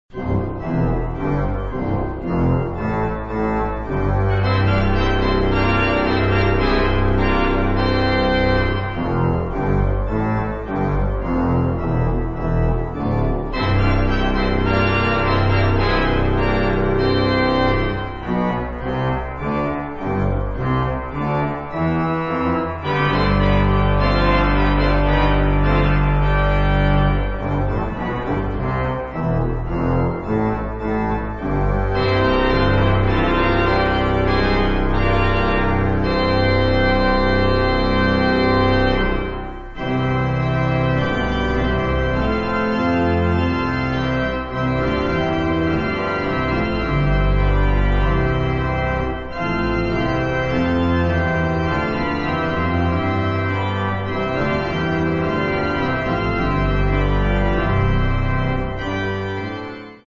harpist
Genre: Hymns, Praise and Gospel